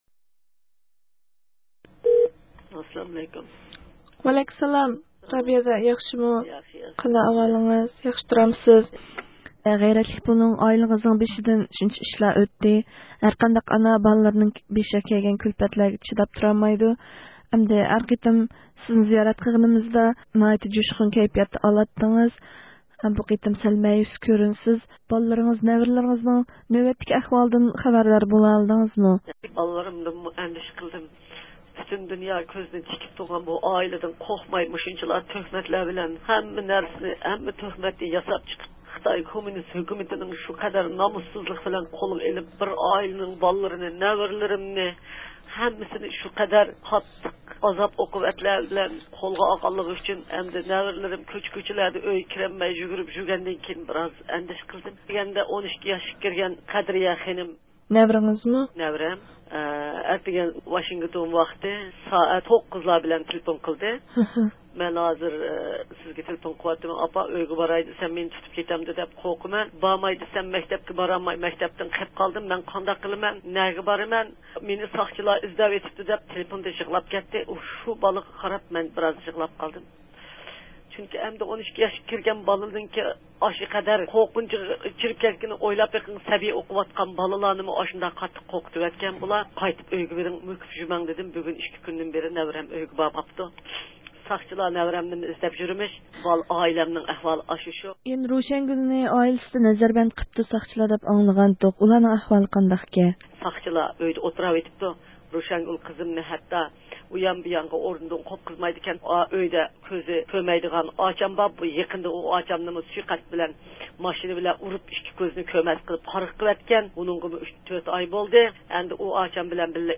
زىيارىتىنى قوبۇل قىلىپ، خىتاينىڭ نەزەربەنتى ئاستىدىكى بالىلىرى ھەم نەۋرىلىرىنىڭ نۆۋەتتىكى ئەھۋاللىرى ھەققىدە توختالدى.